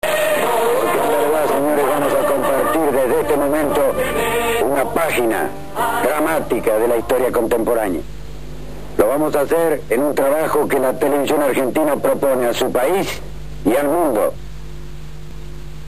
El periodista José Gómez Fuentes lideraba la ola triunfalista desde el noticiero "60 Minutos"
Parte "oficial de guerra"